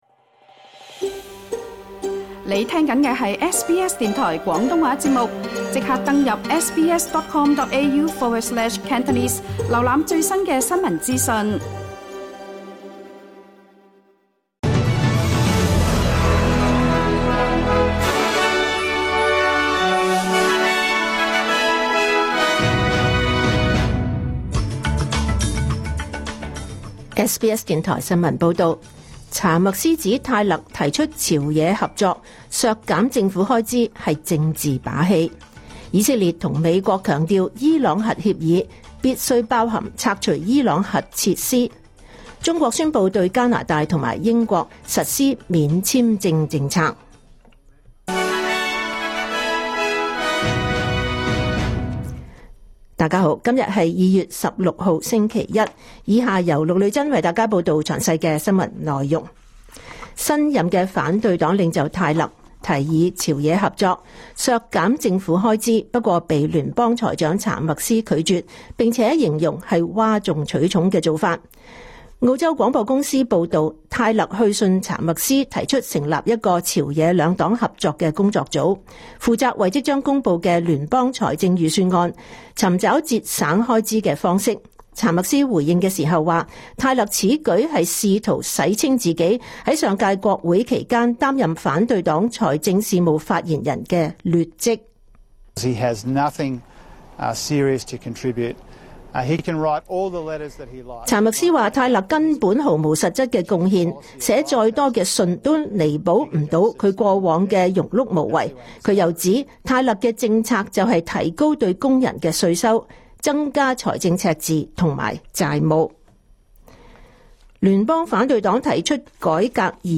2026 年 2 月16 日 SBS 廣東話節目詳盡早晨新聞報道。